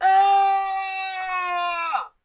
那一定很痛" 一个尖叫声
描述：一声男的尖叫声。没有编辑。使用CA桌面麦克风录制。
标签： 痛苦 尖叫 尖叫 大喊 大叫 必伤 声乐 痛苦 疼痛 声音
声道立体声